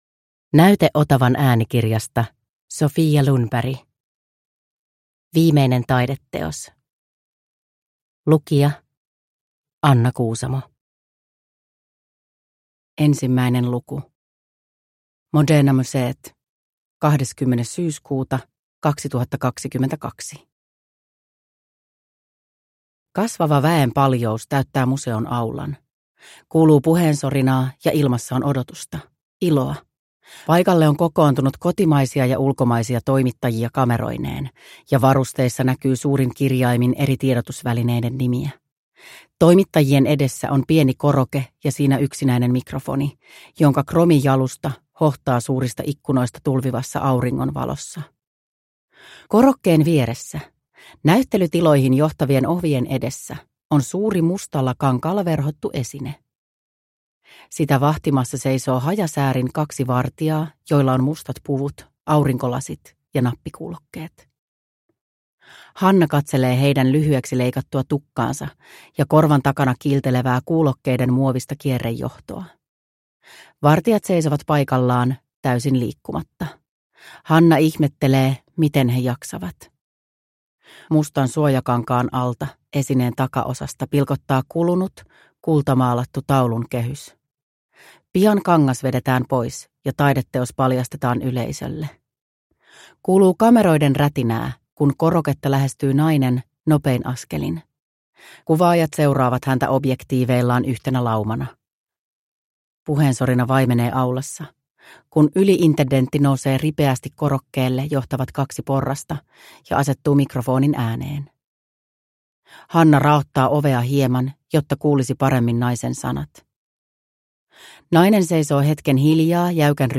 Viimeinen taideteos – Ljudbok – Laddas ner